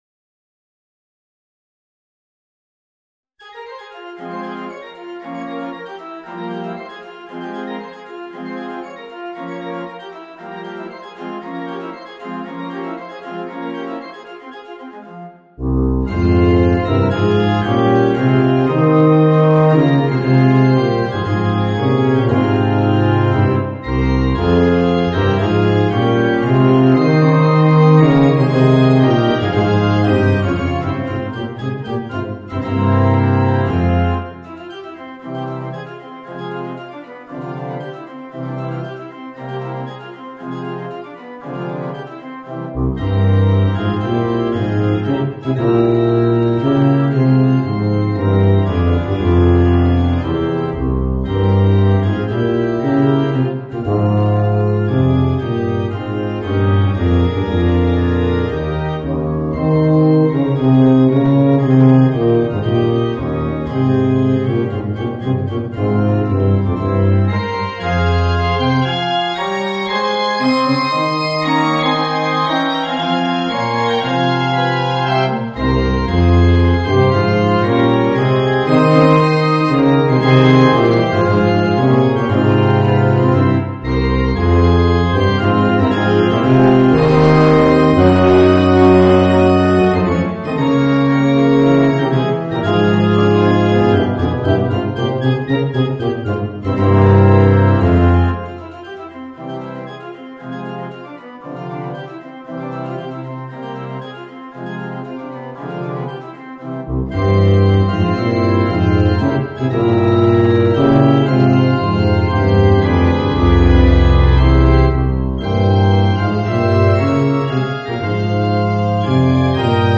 Voicing: Bb Bass and Organ